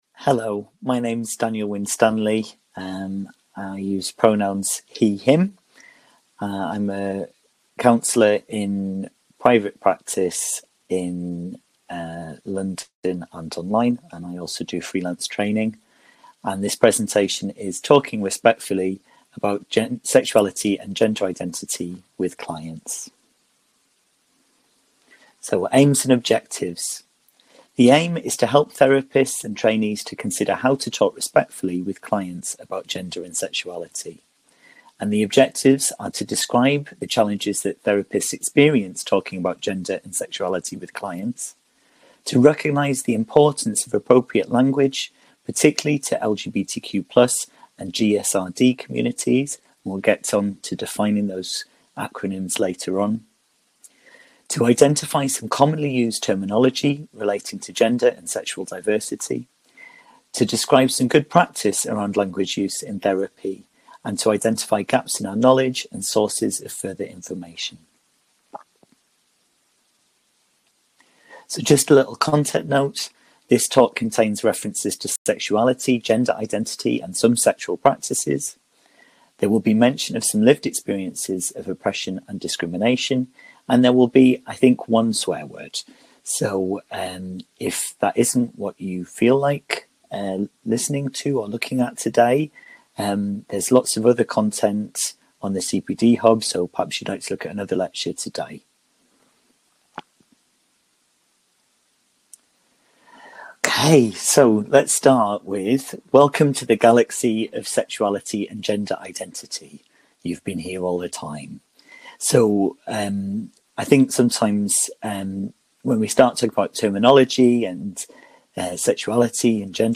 Free CPD Lecture: Talking Respectfully about Sexuality and Gender Identity with Clients • Counselling Tutor
Talking+Respectfully+about+Sexuality+and+Gender+Identity+with+Clients+-+free+CPD+lecture.mp3